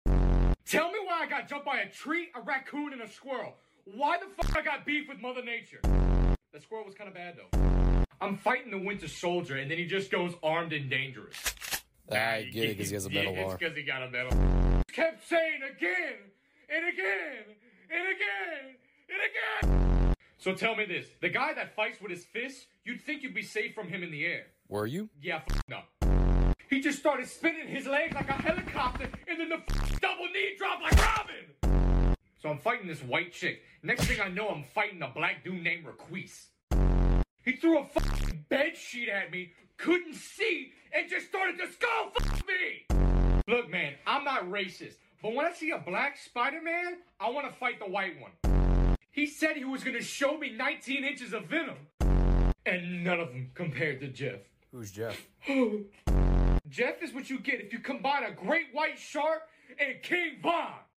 The Marvel Rivals Experience in sound effects free download